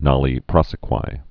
(nŏlē prŏsĭ-kwī, -kwē)